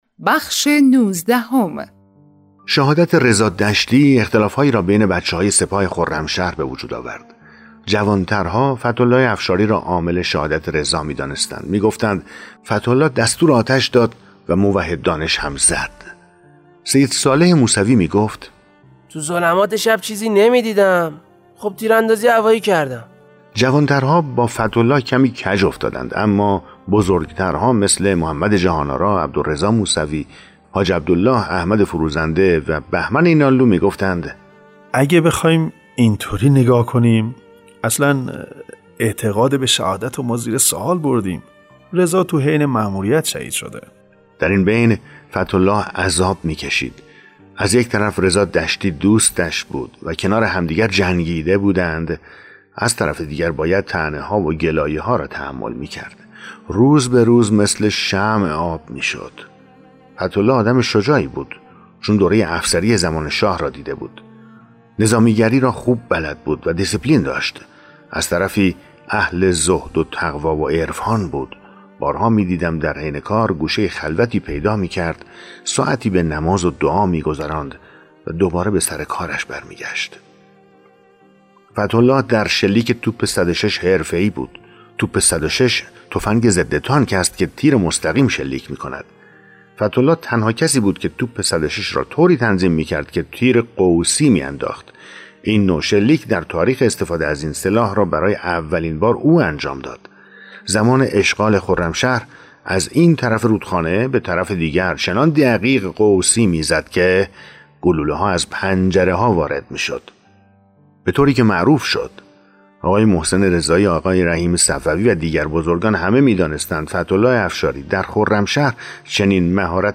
کتاب صوتی «پسر های ننه عبدالله»